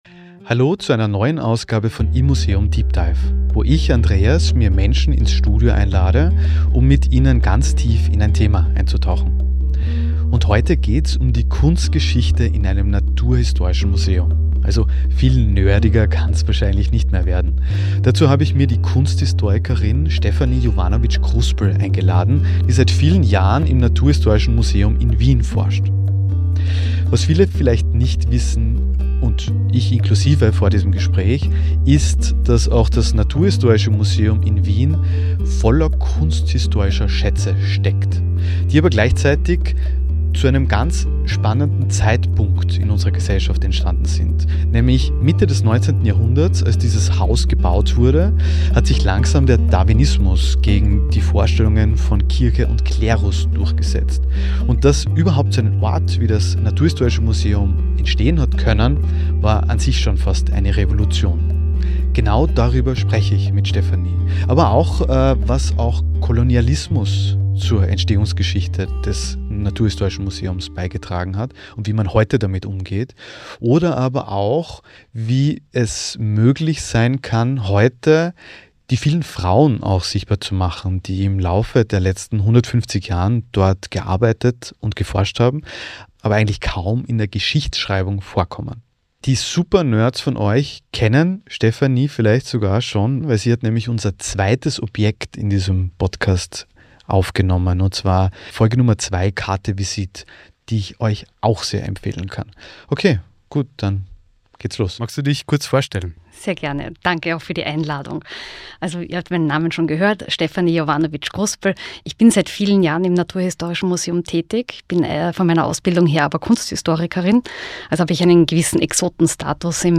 Wir gehen auf Exkursion!